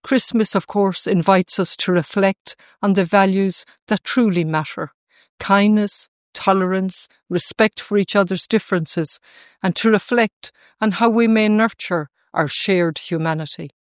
The President has paid tribute to caregivers, members of the Defence Forces serving abroad, and victims of conflicts around the world in her first Christmas message.